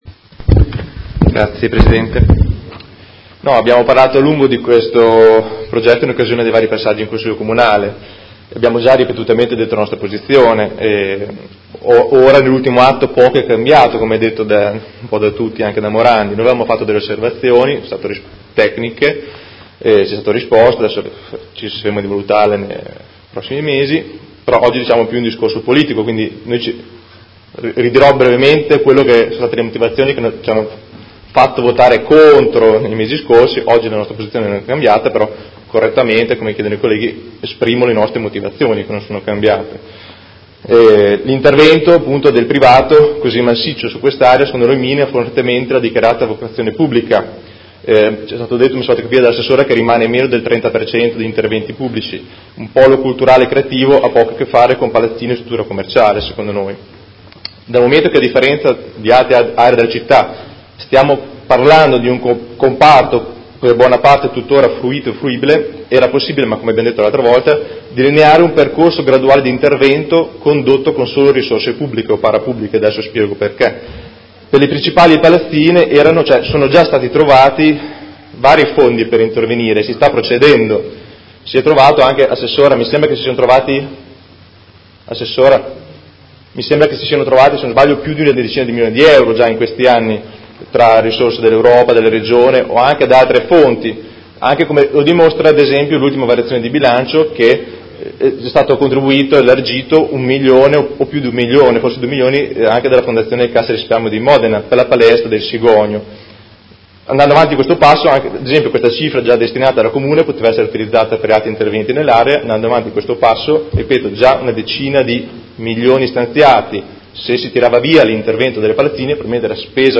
Seduta del 04/04/2019 Dichiarazione di voto.